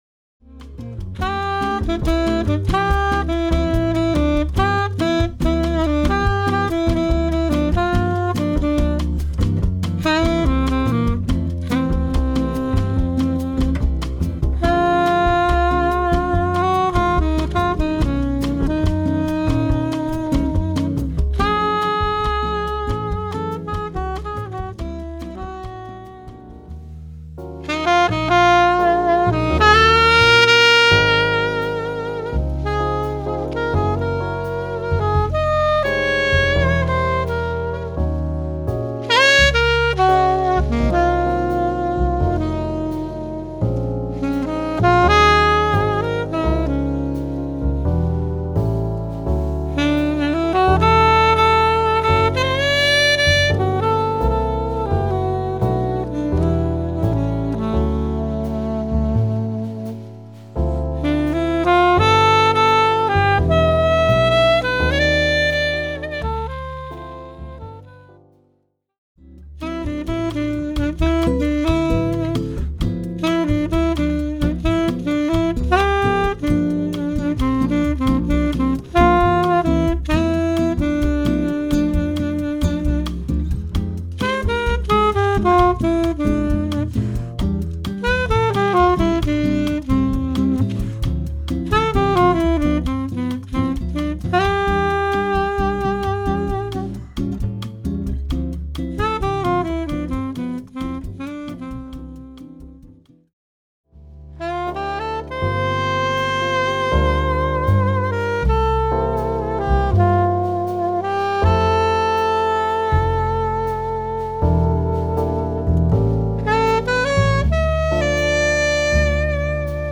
Saxophone. Examples of different playing styles.